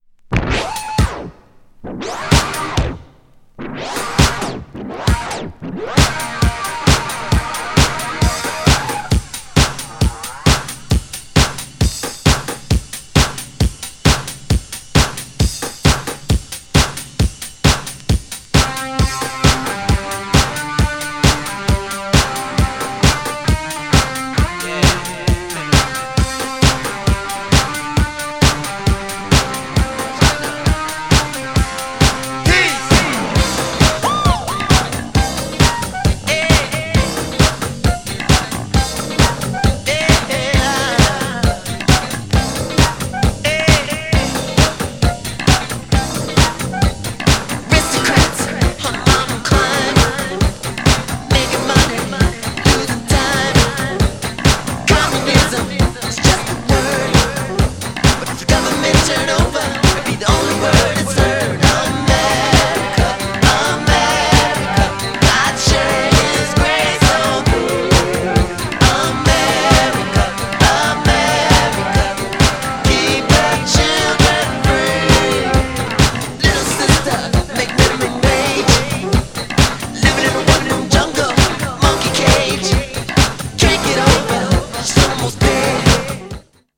GENRE Dance Classic
BPM 126〜130BPM
# GROOVY # JAZZY
# P-FUNK